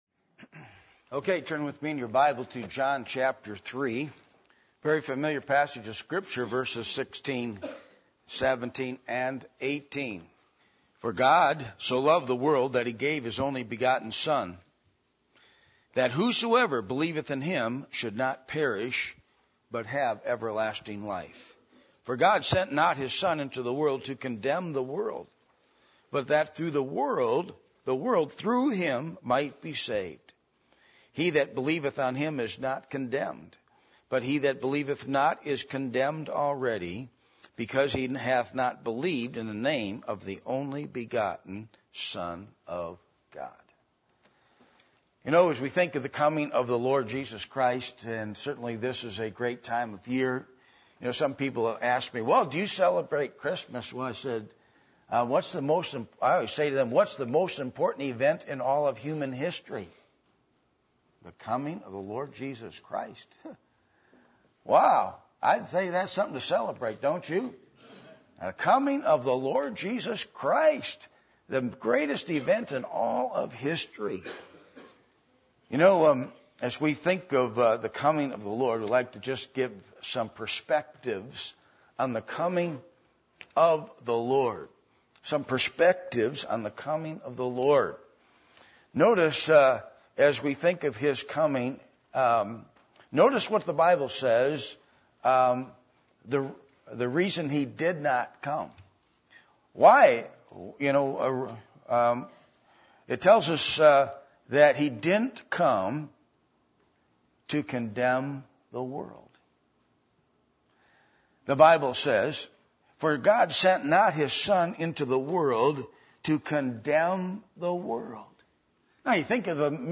Passage: John 3:16-18 Service Type: Sunday Morning %todo_render% « A Healthy Spiritual Vision Is Disney Christian Family Friendly?